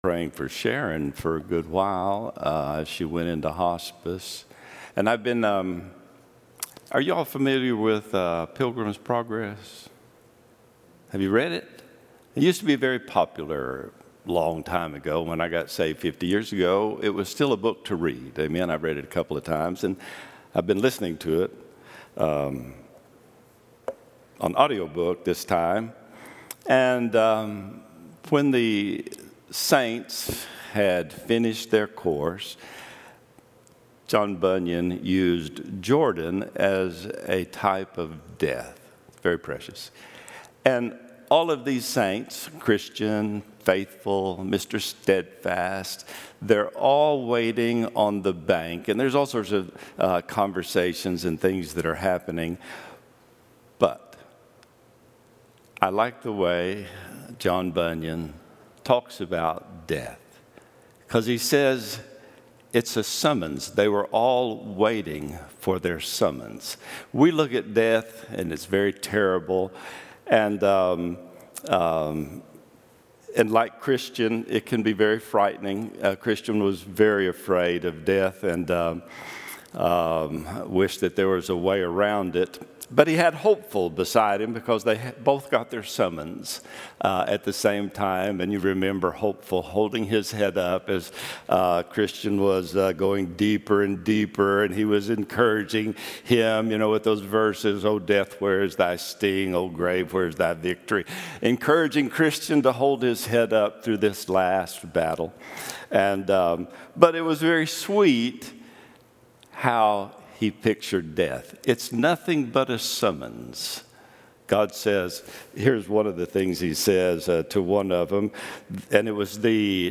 11-24-24 Sunday School | Buffalo Ridge Baptist Church